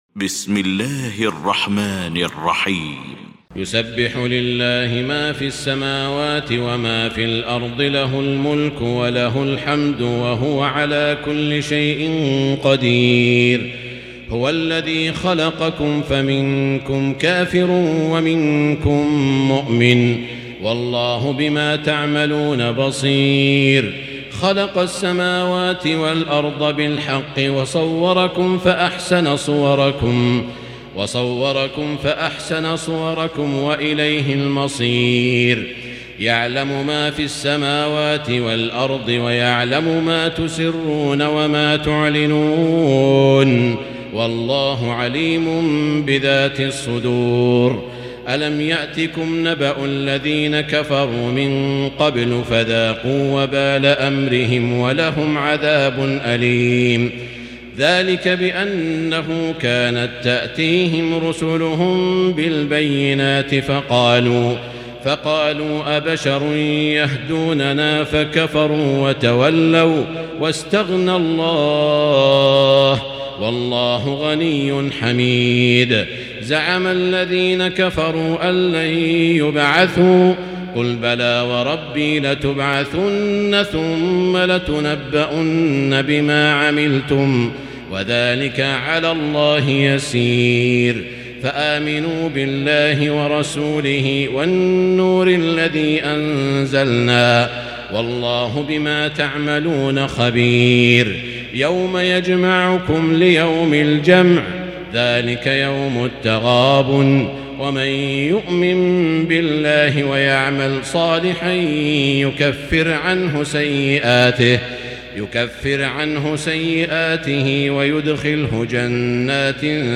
المكان: المسجد الحرام الشيخ: سعود الشريم سعود الشريم التغابن